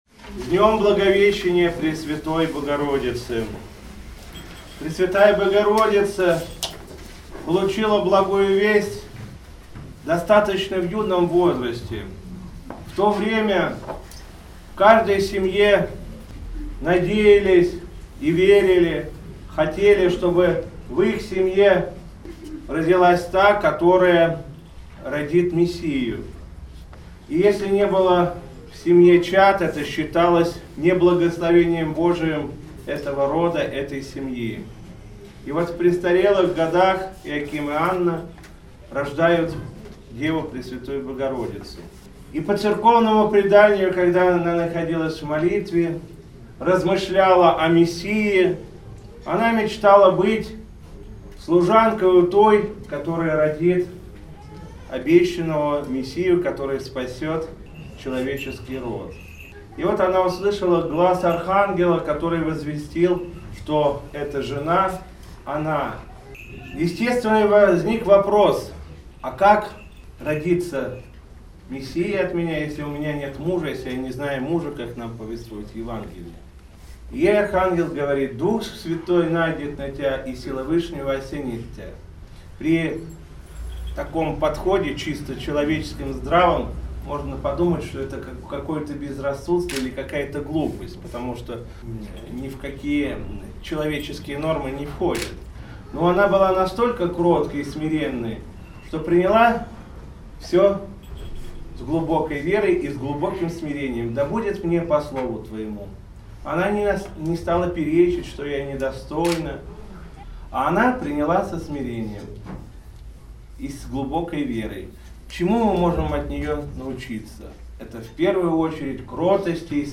Затем владыка Игнатий обратился к собравшимся с архипастырским словом.
Митрополит Игнатий. Слово после Литургии в праздник Благовещения